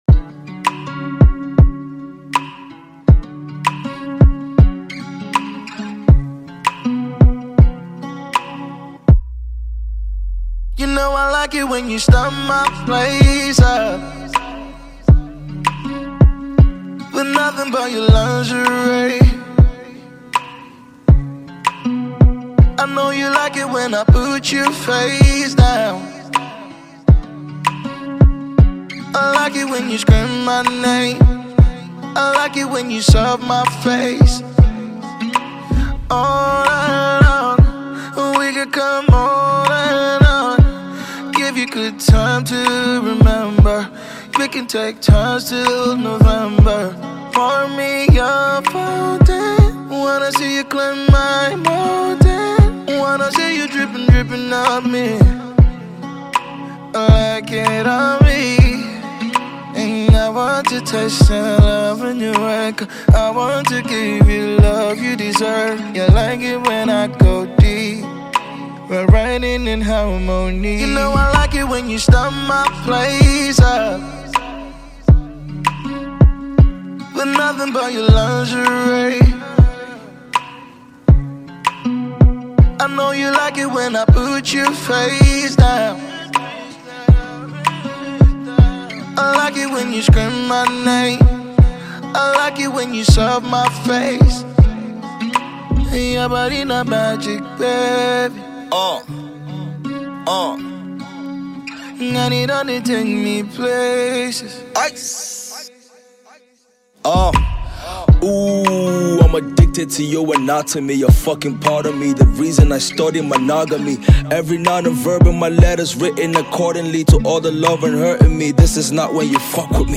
Afrobeat, Afro-Soul and rap track